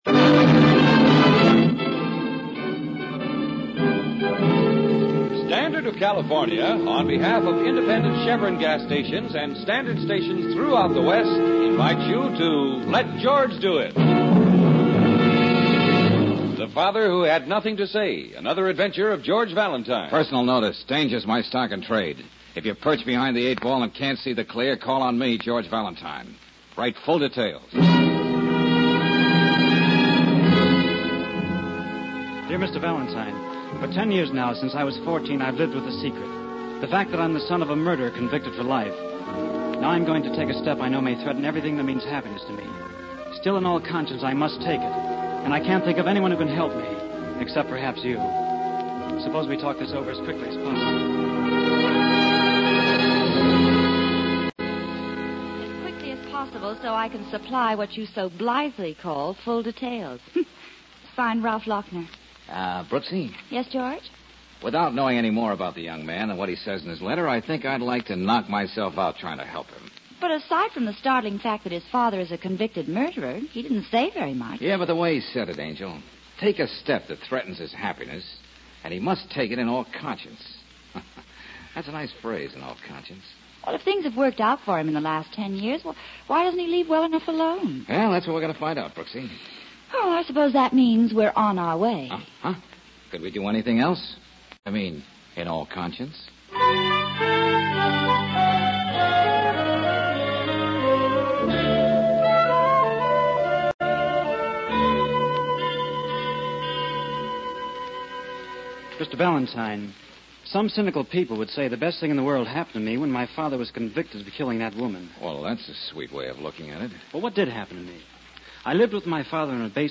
Let George Do It is an American radio drama series produced from 1946 to 1954 by Owen and Pauline Vinson. Bob Bailey starred as private investigator George Valentine; Olan Soule voiced the role in 1954.
The program then changed into a suspenseful tough guy private eye series.